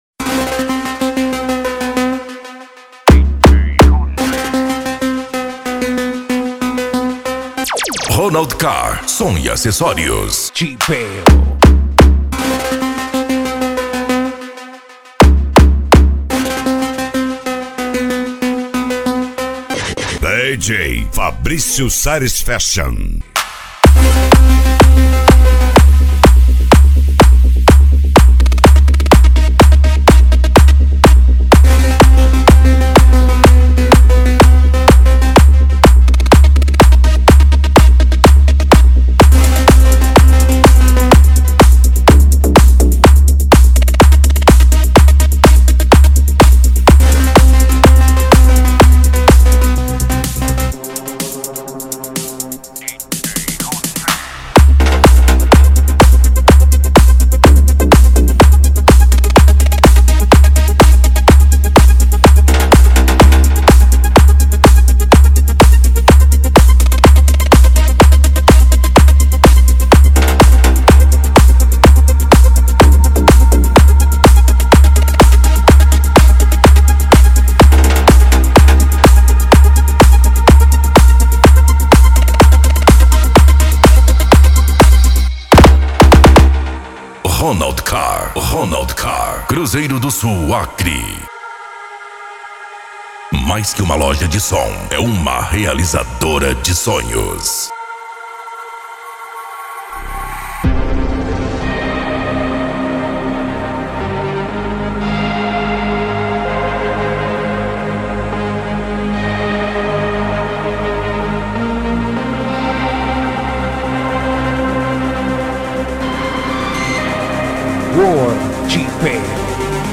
Bass
Deep House
Euro Dance